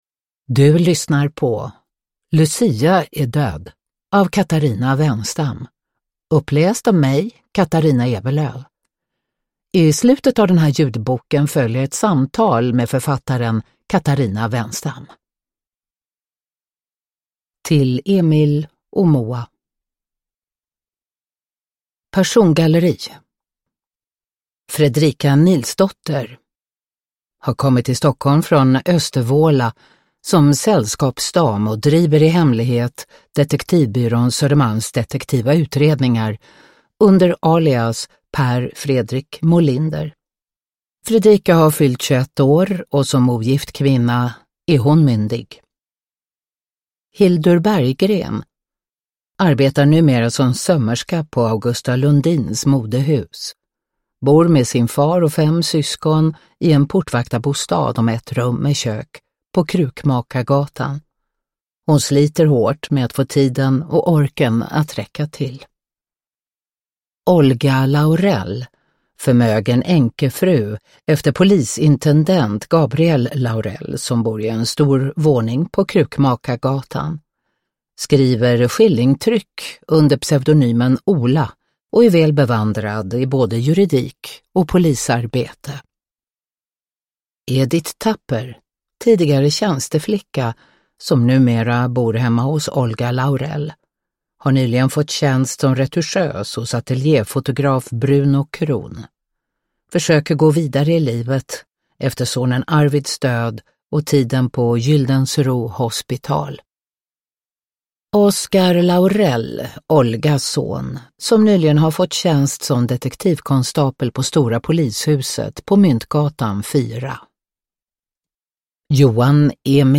Lucia är död – Ljudbok
Uppläsare: Katarina Ewerlöf